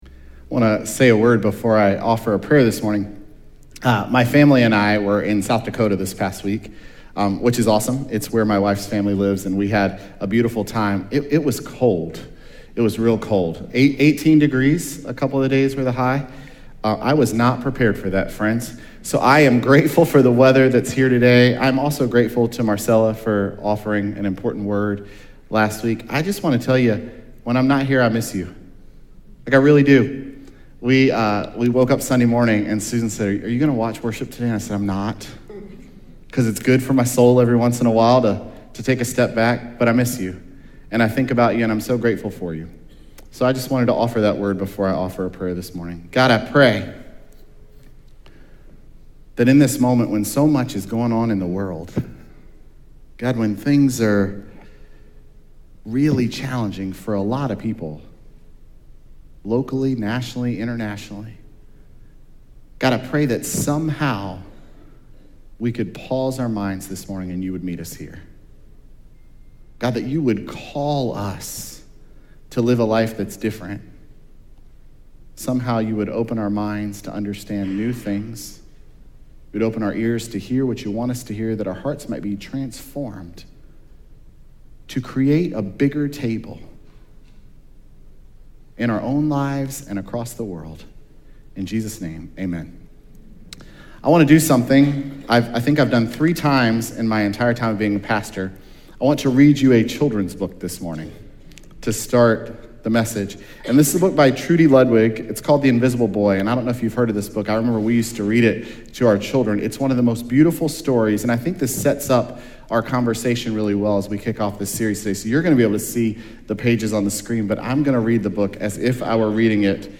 Nov5Sermon.mp3